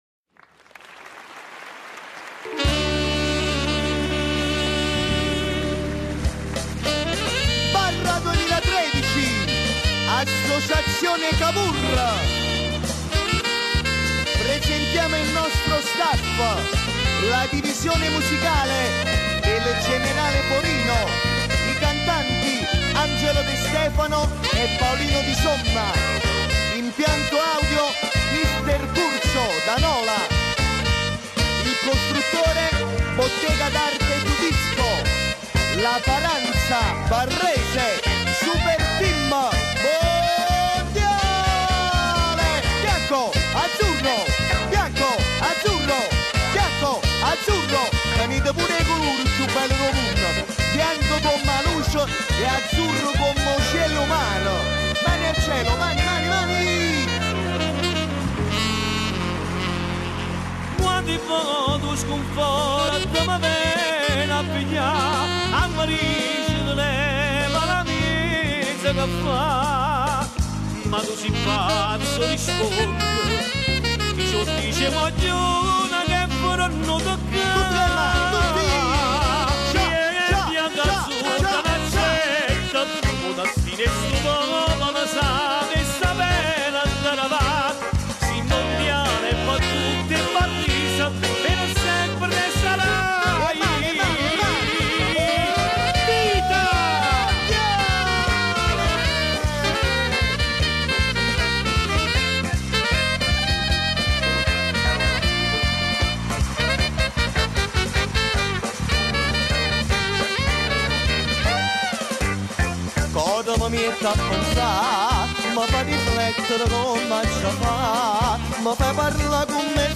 Canzoni d'occasione dell'Associazione Cavour 2013 paranza Barrese La Mondiale
Medley live8.mp3